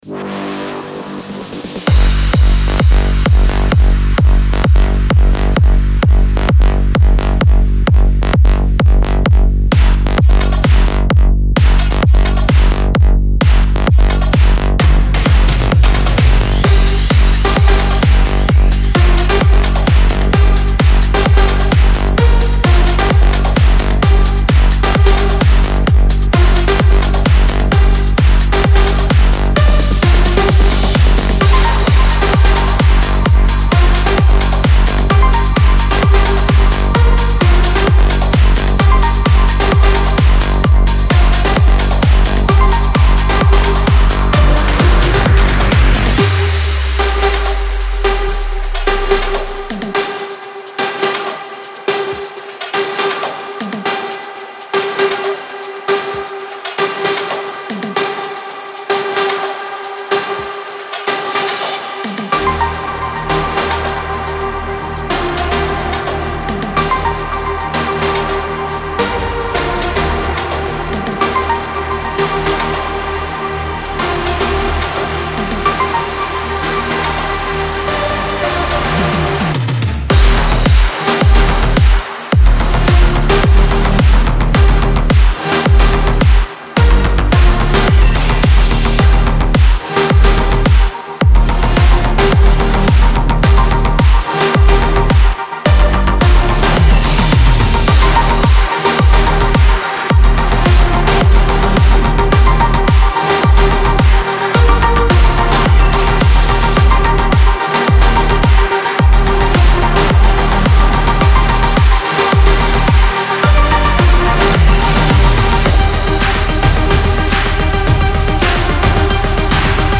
ترنس